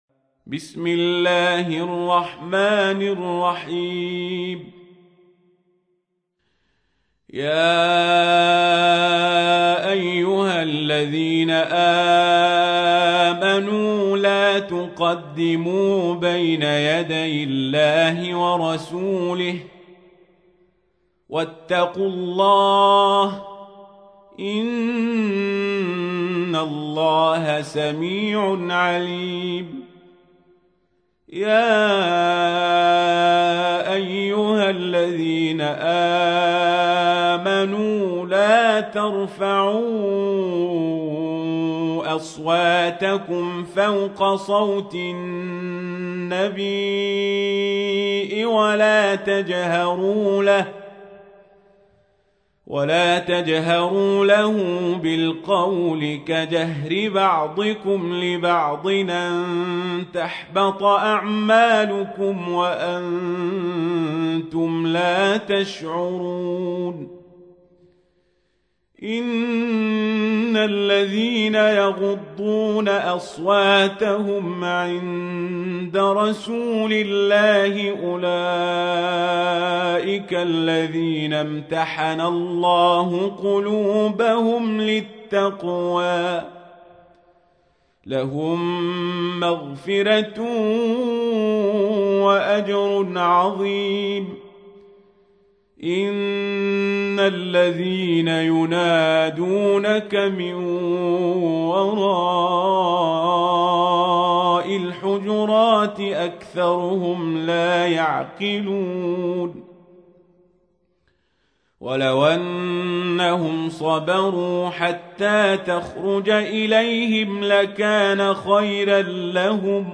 تحميل : 49. سورة الحجرات / القارئ القزابري / القرآن الكريم / موقع يا حسين